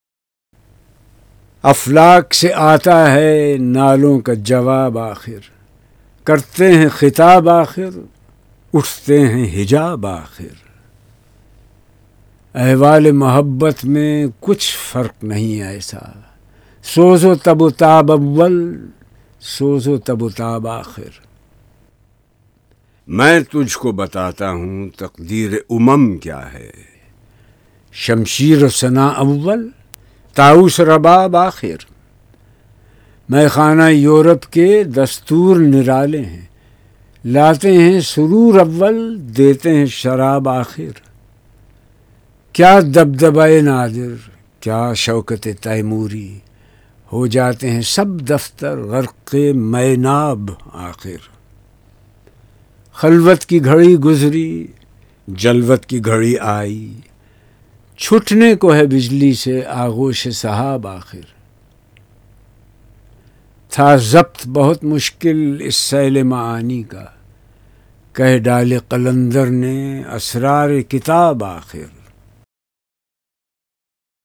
Zia Muhauddin Reads Bal e Jibreel - International Iqbal Society - Allama Iqbal